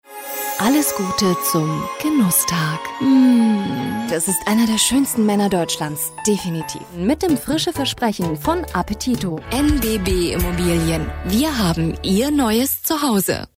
Studio-Sprecherin mit junger, frischer Stimme.
norddeutsch
Sprechprobe: Werbung (Muttersprache):